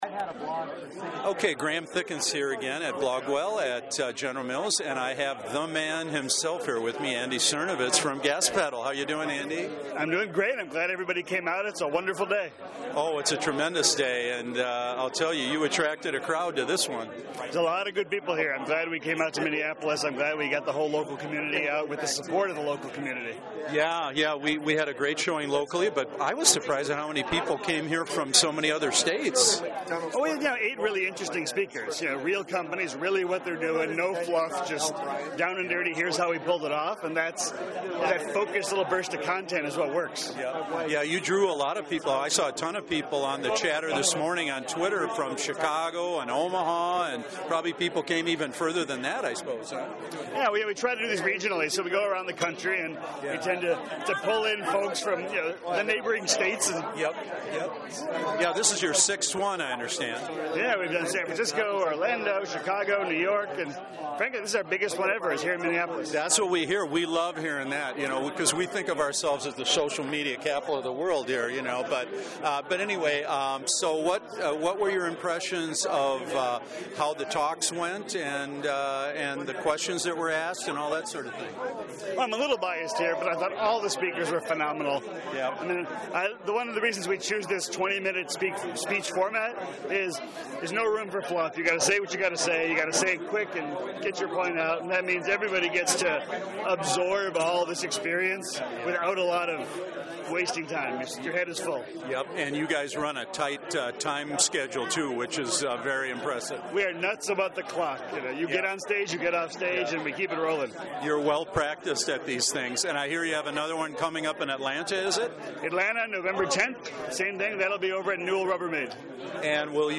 My Interviews: I took along my trusty studio-grade handheld recorder (the Olympus LS-10) and grabbed seven brief audio interviews before and after the sessions, and during breaks. The last two featured wine, so you’ll have to excuse the background merriment.